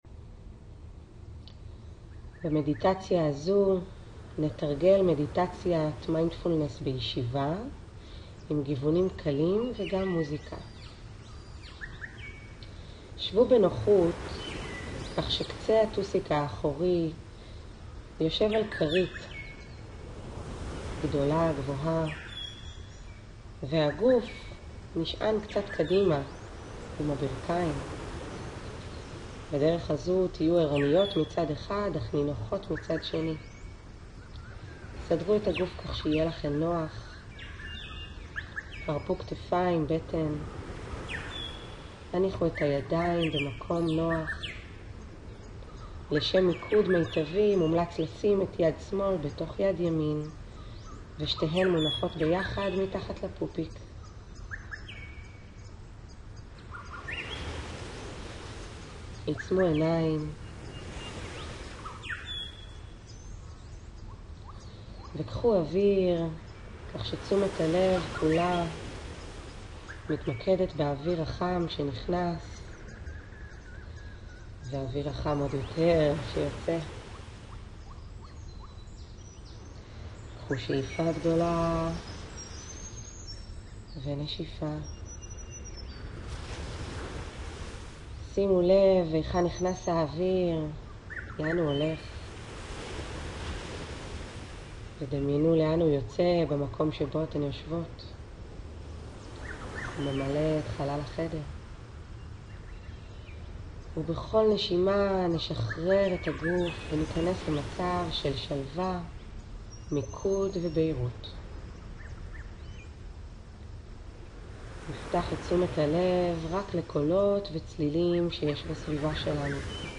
מיינדפולנס+בישיבה+ומוסיקה.mp3